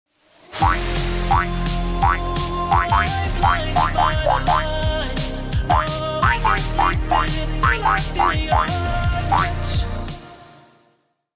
Song 4: Retro-Vibes pur!